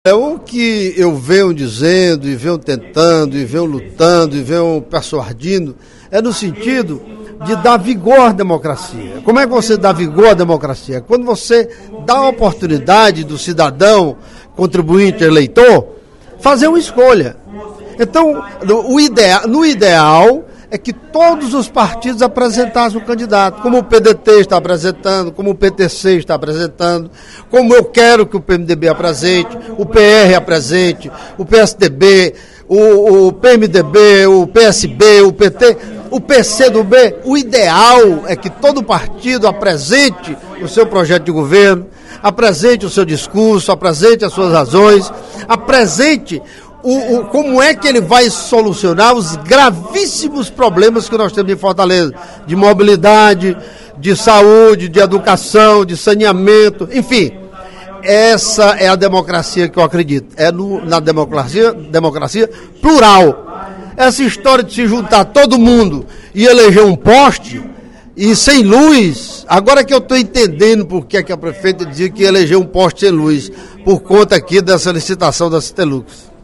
O deputado Carlomano Marques (PMDB) afirmou, nesta terça-feira (06/03) em Plenário, que ficou feliz ao ver o lançamento da pré-candidatura do deputado Heitor Férrer (PDT) à Prefeitura de Fortaleza.